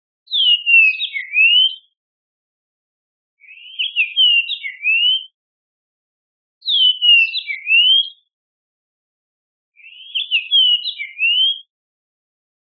イカル（鵤・桑鳲）のさえずり 着信音
林によく通る声で「キコーキー」と鳴きます。黄色いくちばしが特徴的で美しい鳴き声を持つ野鳥です。